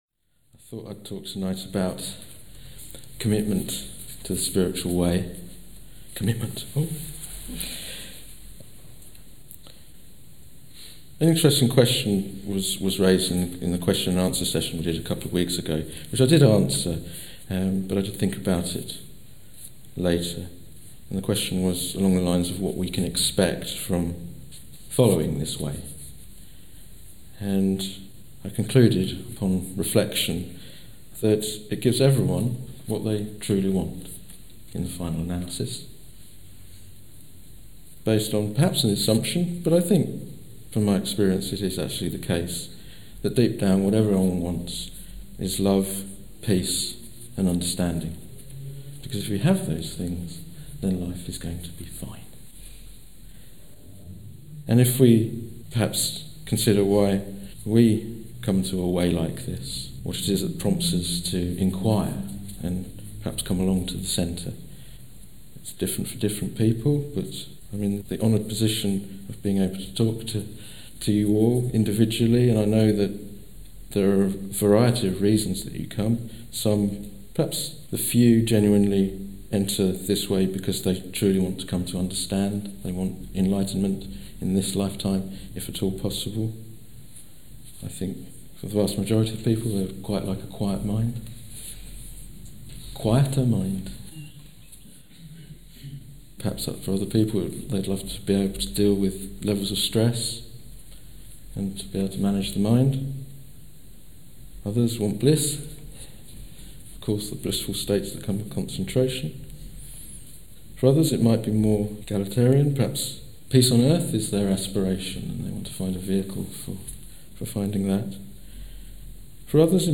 The cost of following the Buddha's way is in giving up our choices and options. This talk explains two levels of commitment: external and internal, and demonstrates how the recollection of death helps to clarify one's priorities in life. Enlightenment is rare but entirely possible with the right commitment.